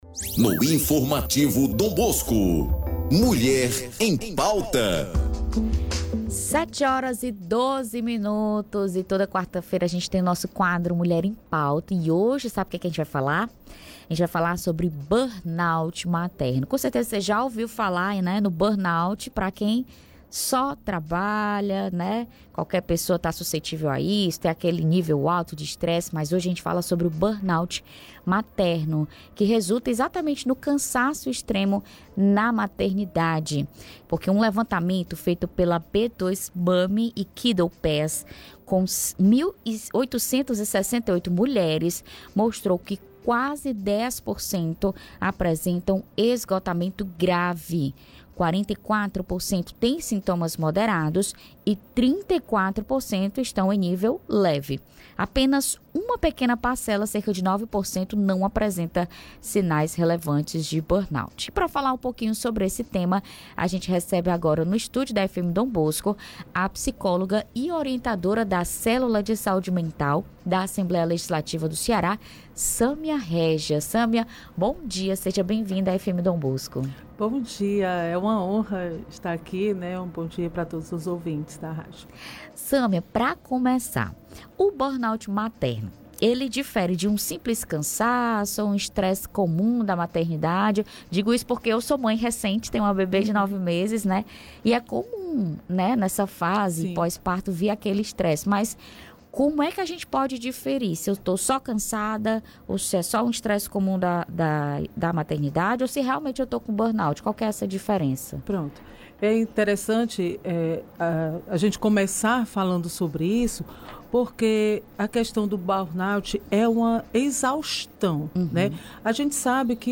ENTREVISTA_BORNOUT-MATERNO-1510.mp3